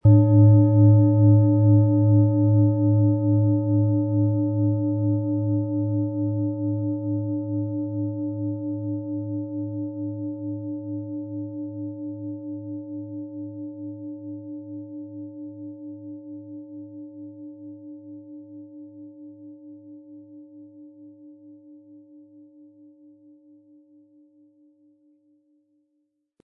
Tibetische Schulter-Kopf-Becken- und Bauch-Klangschale, Ø 24,4 cm, 1300-1400 Gramm, mit Klöppel
Tibetische Schulter-Kopf-Becken- und Bauch-Klangschale
Im Sound-Player - Jetzt reinhören hören Sie den Original-Ton dieser Schale. Wir haben versucht den Ton so authentisch wie machbar hörbar zu machen, damit Sie hören können, wie die Klangschale bei Ihnen klingen wird.